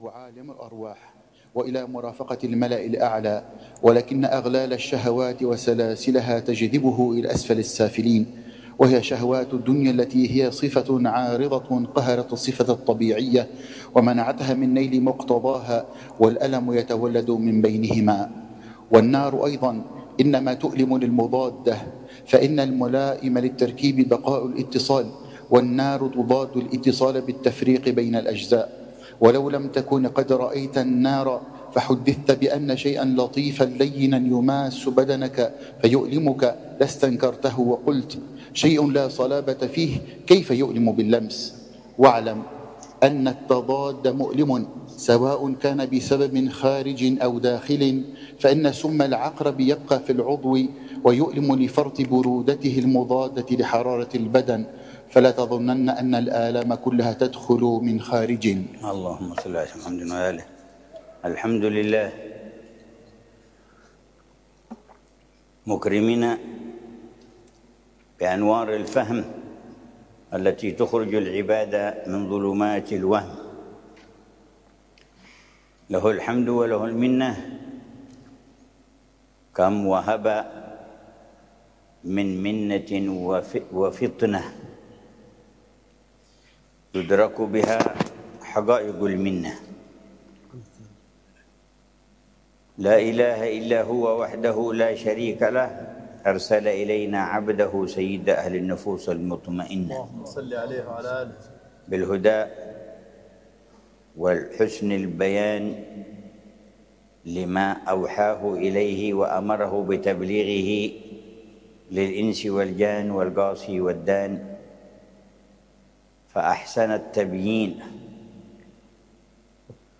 الدرس ال47 في شرح الأربعين في أصول الدين: فتح باب الفهم لخصائص الروح، وأسباب آلامها، وطريق عودتها إلى صفائها الأول